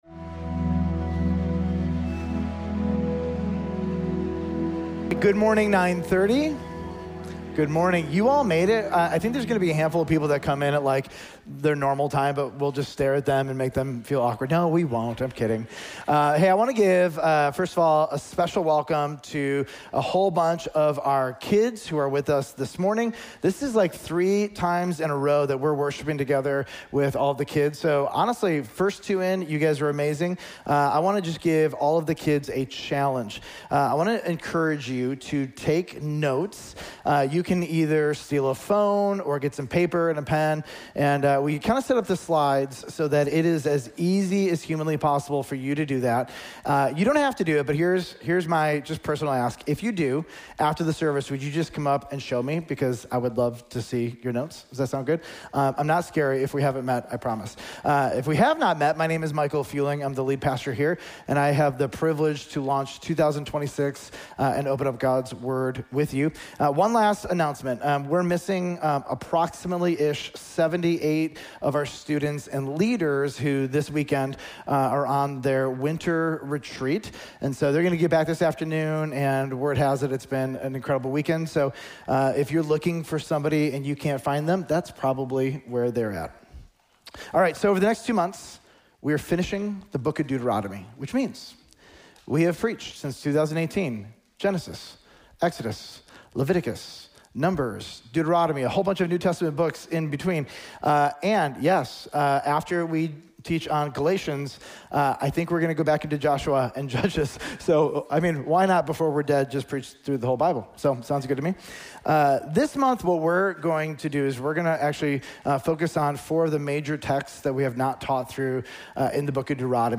Village Church of Bartlett: Sermons